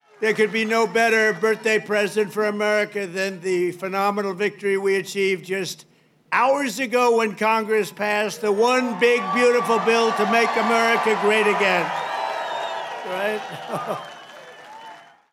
(Radio Iowa) – President Donald Trump celebrated recent victories with a large crowd on the Iowa State Fairgrounds last (Thursday) night.
Trump began his speech by reminiscing about past Iowa victories in the 2016, 2020 and 2024 presidential campaigns — and praising Iowans for being loyal.